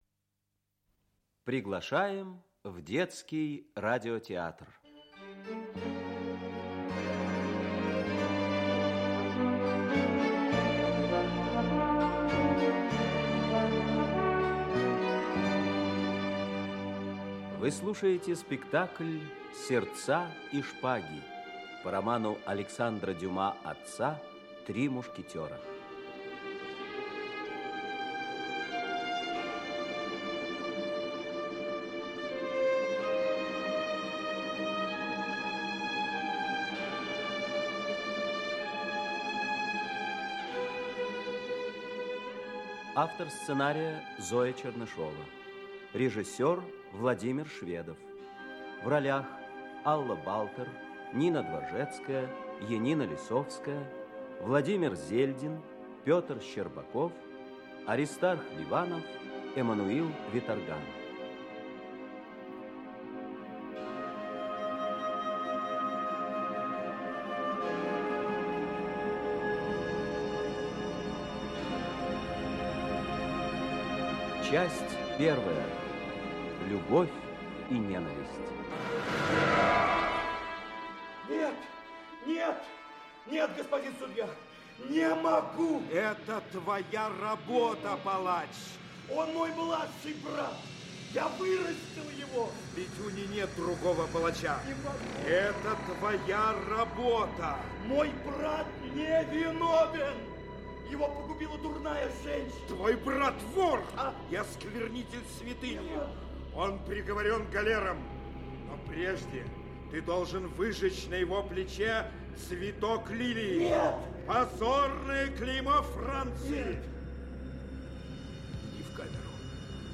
Аудиокнига Сердца и шпаги (спектакль) | Библиотека аудиокниг
Aудиокнига Сердца и шпаги (спектакль) Автор Александр Дюма Читает аудиокнигу Эммануил Виторган.